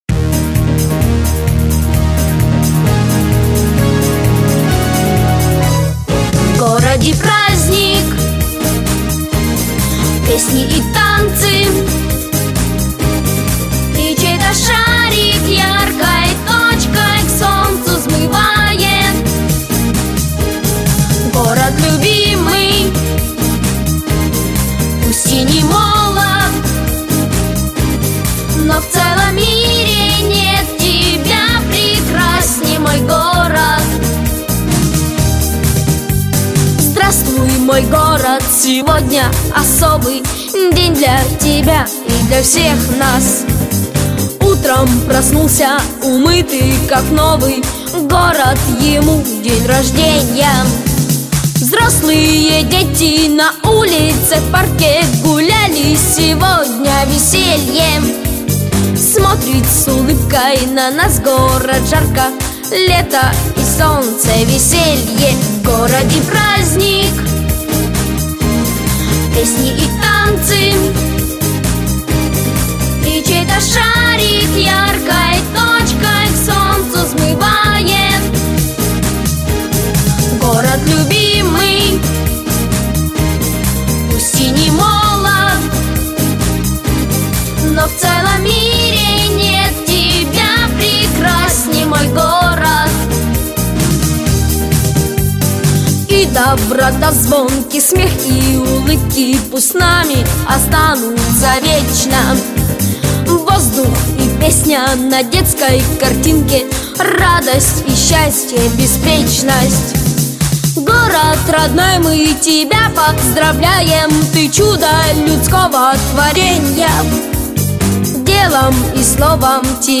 • Категория: Детские песни
Детская эстрадная вокальная студия